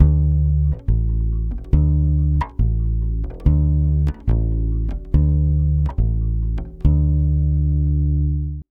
140BASS D7 1.wav